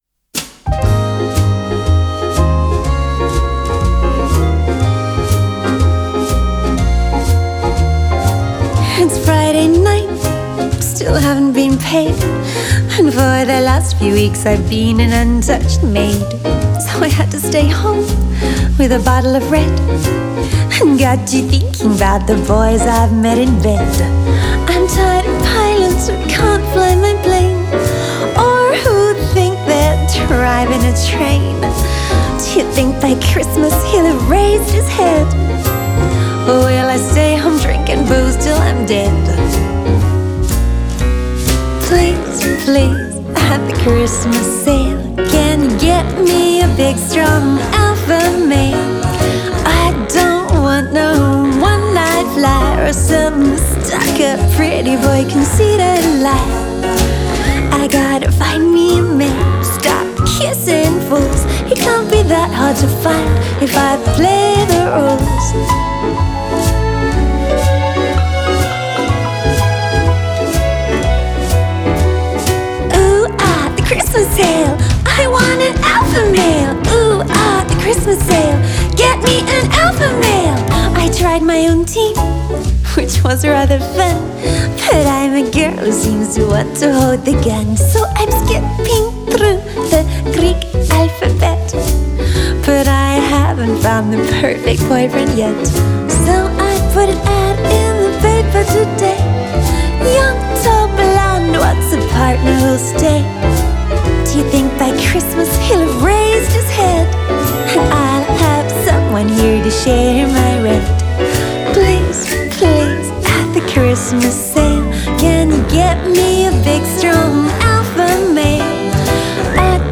piano and backing vocals
guitar
drums